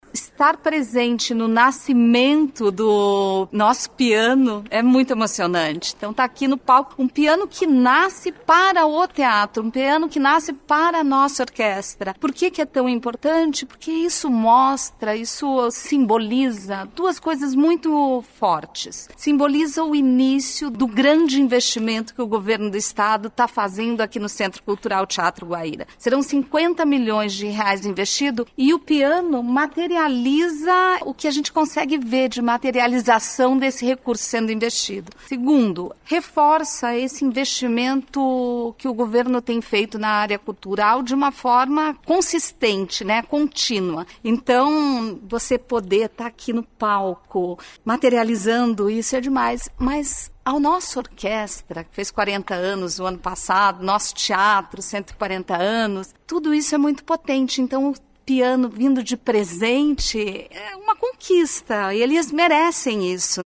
Sonora da secretária da Cultura, Luciana Casagrande Pereira, sobre o novo piano do Teatro Guaíra